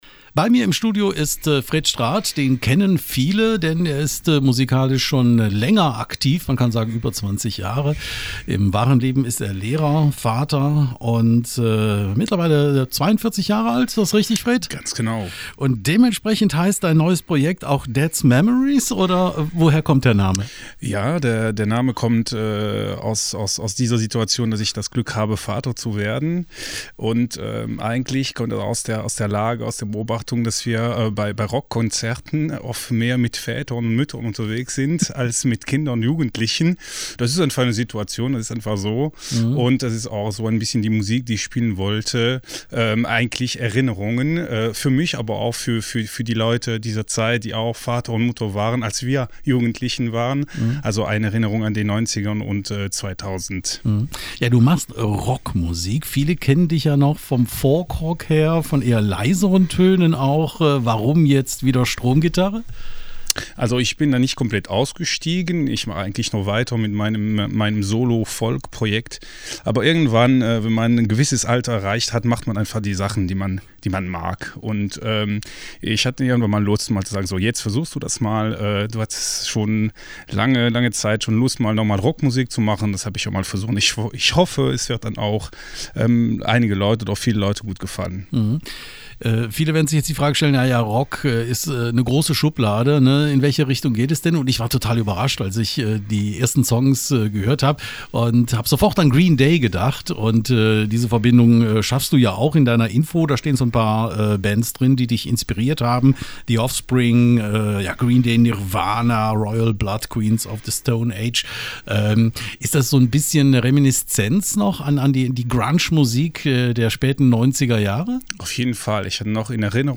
Musik gibt es natürlich auch